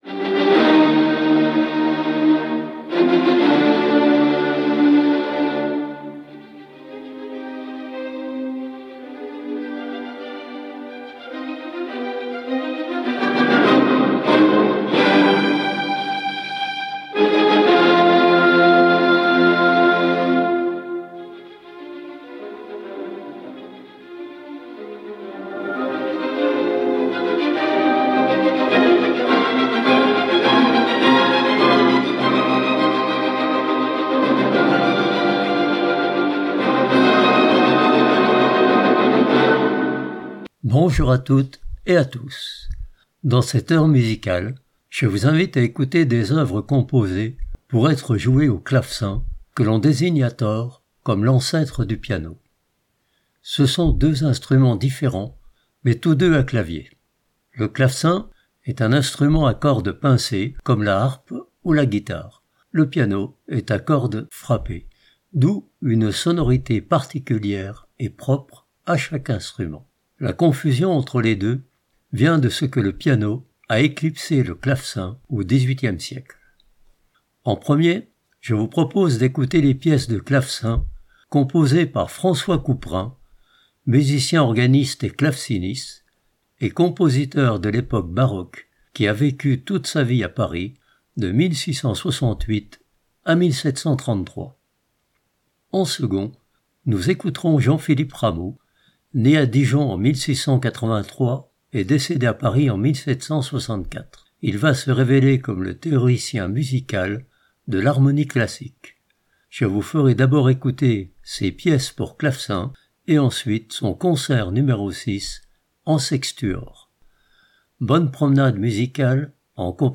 1 heure de musique